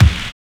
APPLAUSE K.wav